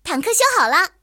野牛修理完成提醒语言.OGG